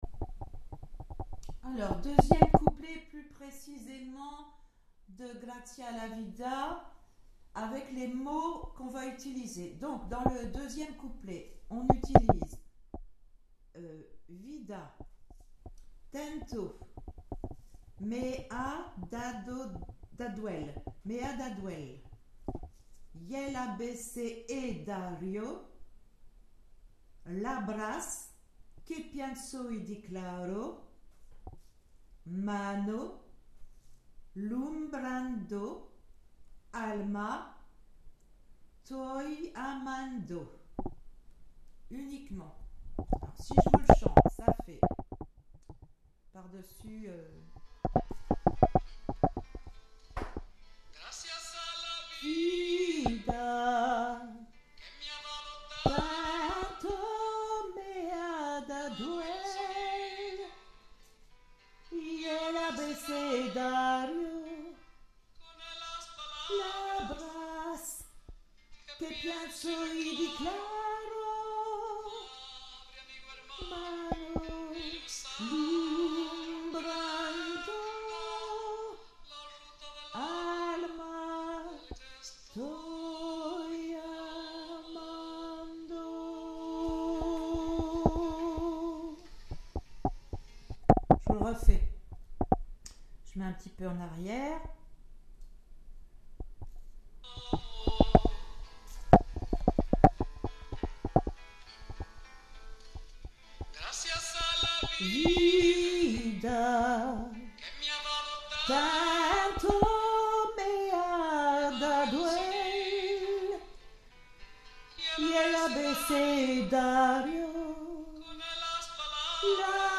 Gracias Alti basses 2 avec paroles